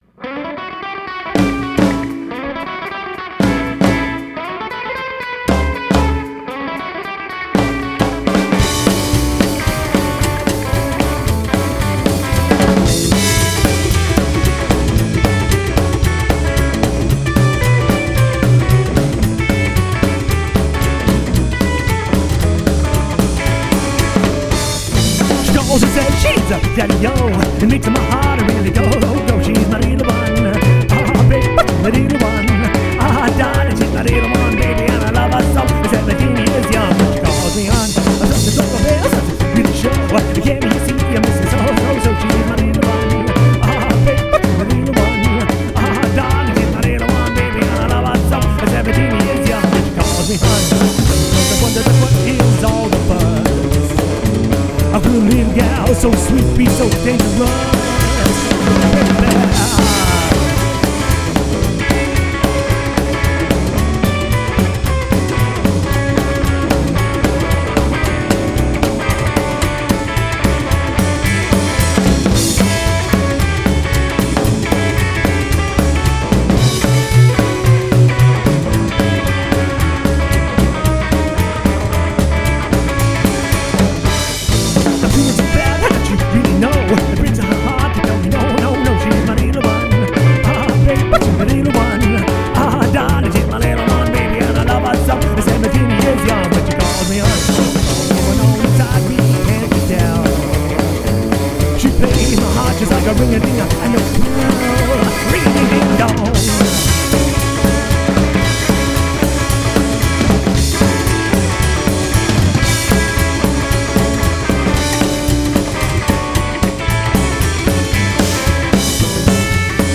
Good times set to music.